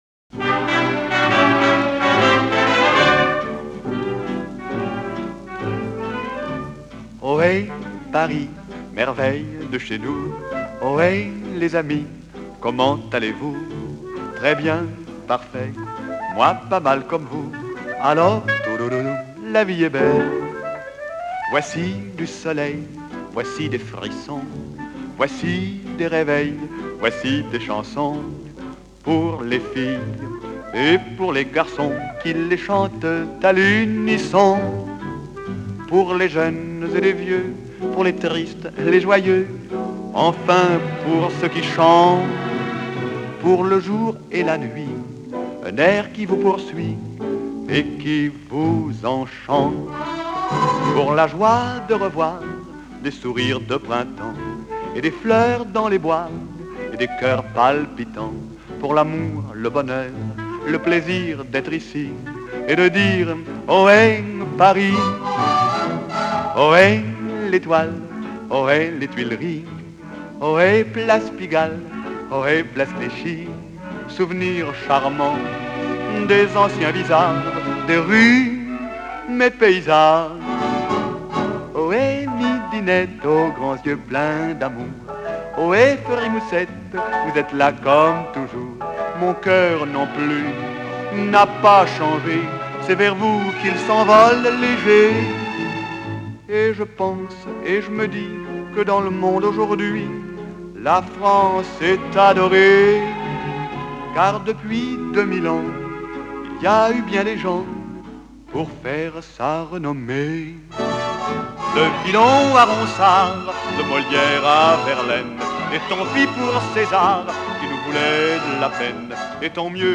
Французская эстрада